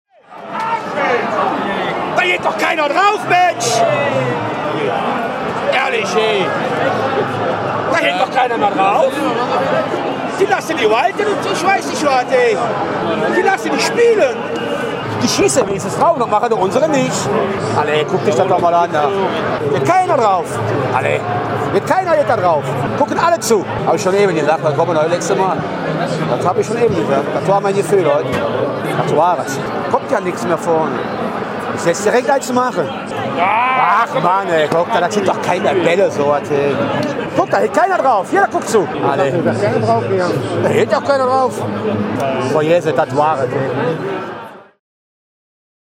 Beim Public Viewing ging die gute Anfangsstimmung der (ost)belgischen Fans erstmal „beim Teufel“.